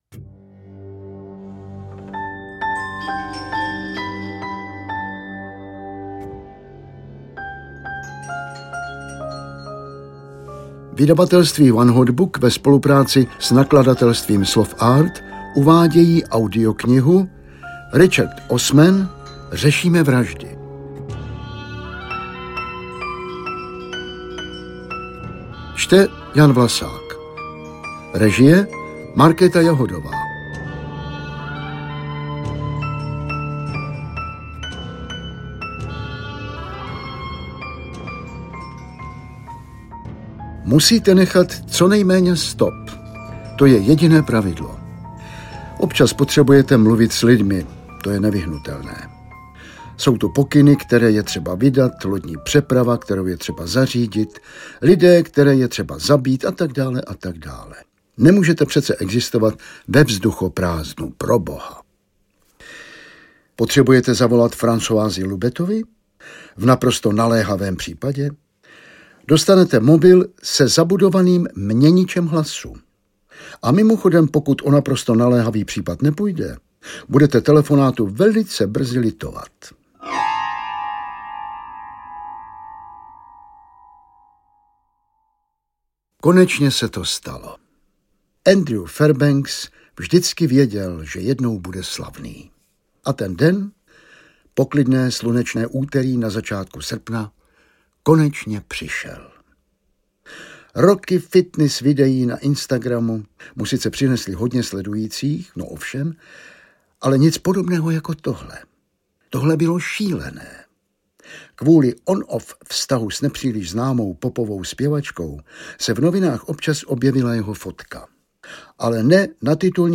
Řešíme vraždy audiokniha
Ukázka z knihy